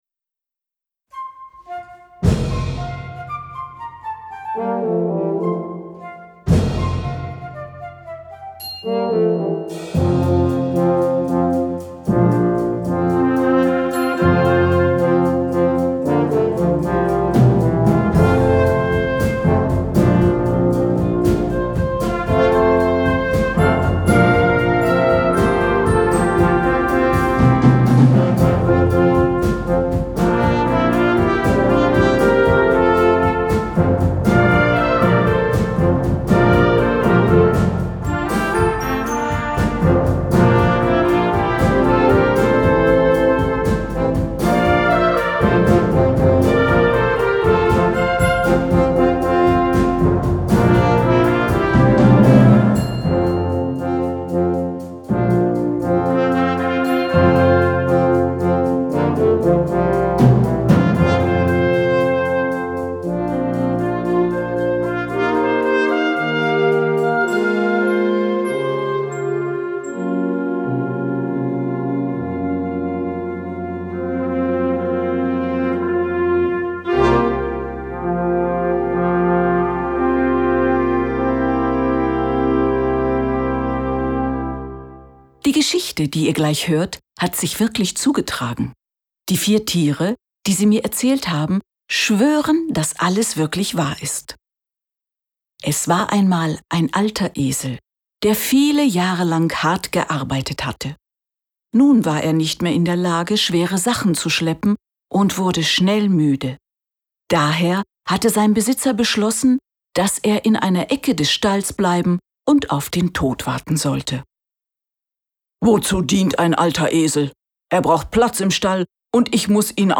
Flex 5 + Erzähler
Ensemble gemischt